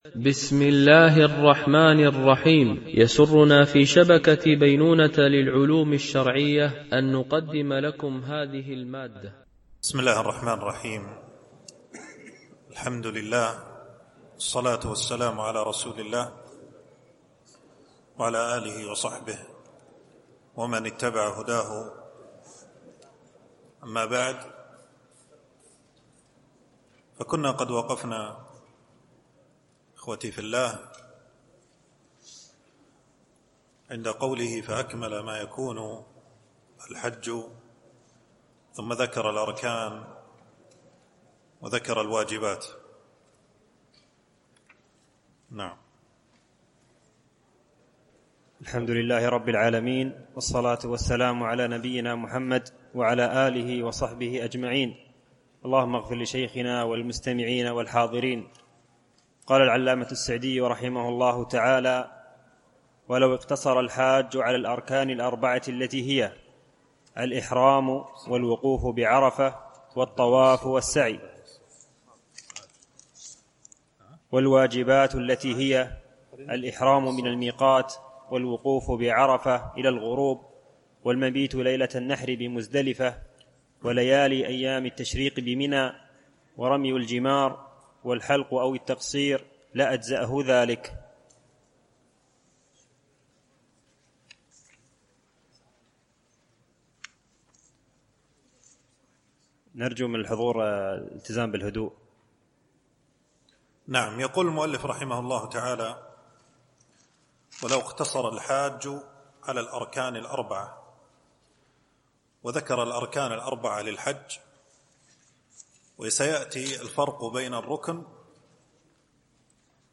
دورة علمية شرعية: لمجموعة من المشايخ الفضلاء، بمسجد عائشة أم المؤمنين - دبي (القوز 4)